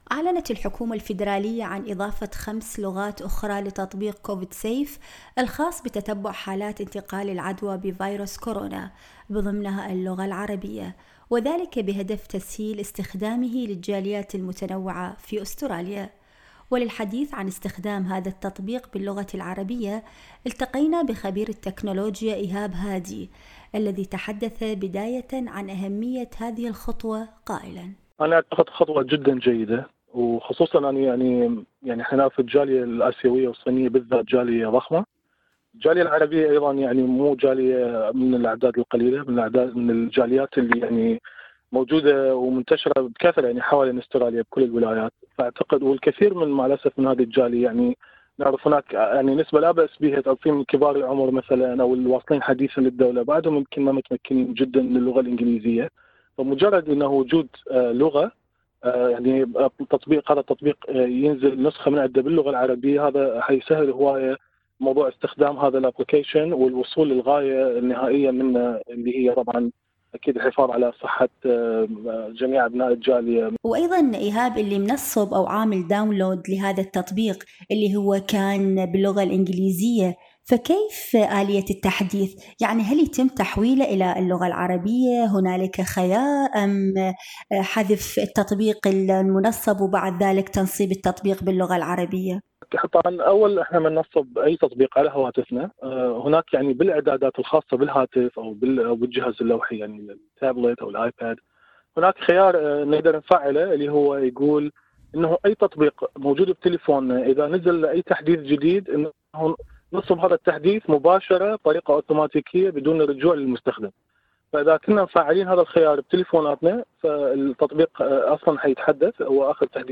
في حديثِ مع إذاعة اس بي أس عربي24